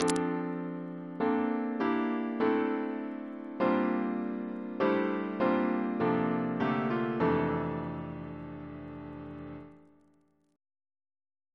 Single chant in F Composer: David Hurd (b.1950) Reference psalters: ACP: 316